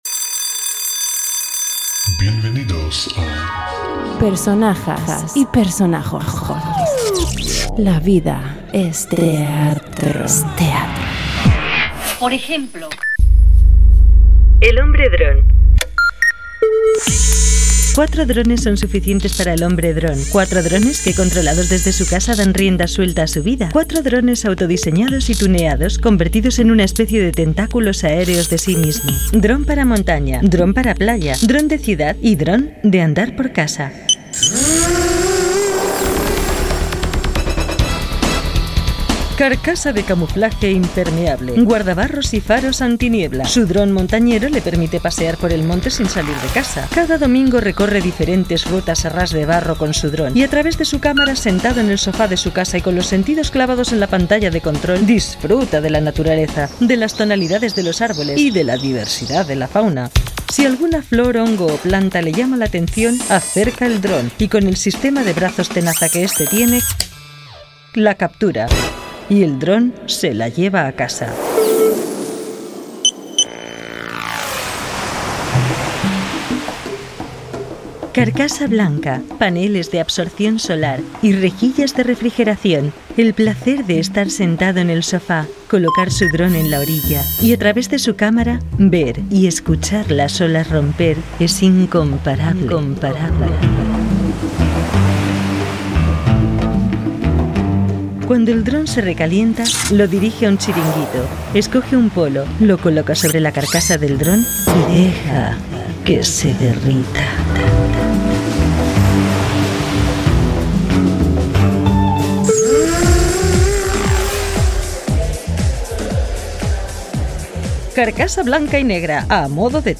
Audio: Ficción Sonora en el Boulevard de Radio Euskadi. En el capítulo 17 las aventuras y desventuras de una nueva especie, "el hombre dron".